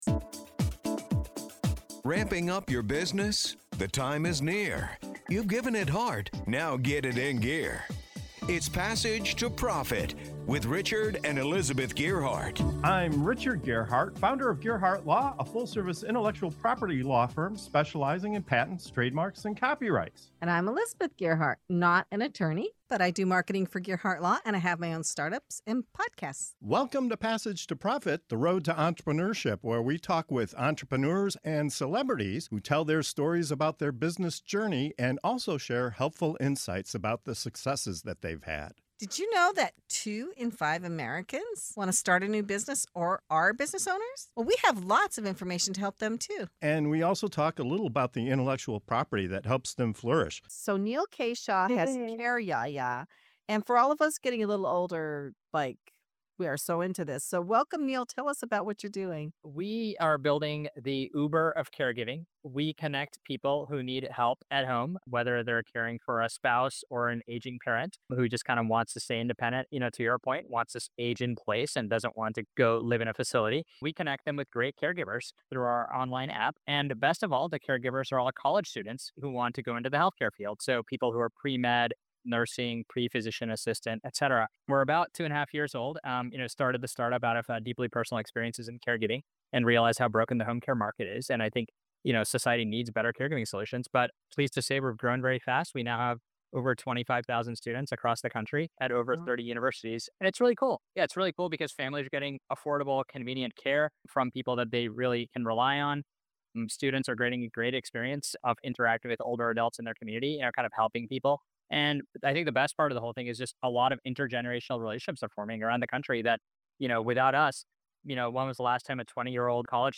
Discover how CareYaya is making quality in-home care more affordable, bridging generations, and addressing the growing caregiver shortage with innovation, heart, and community-driven solutions. Don't miss this forward-thinking conversation about aging, technology, and the power of connection!